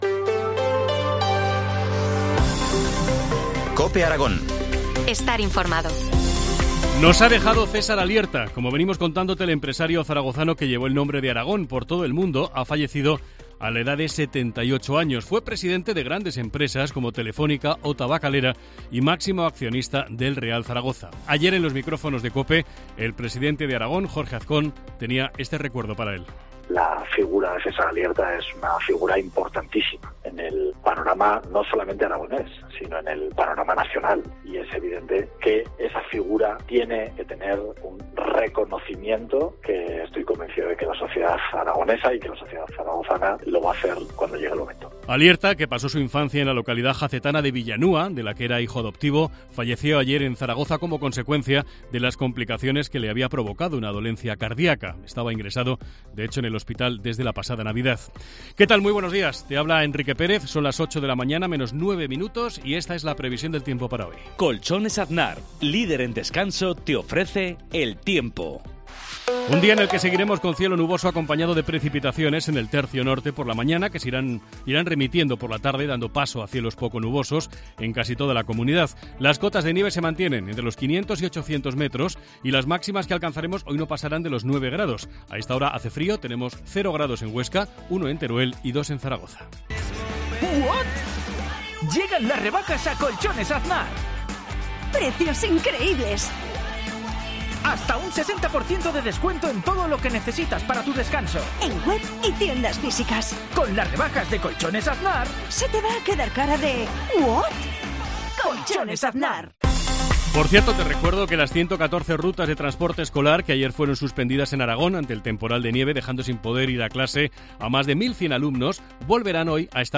AUDIO: Titulares del día en COPE Aragón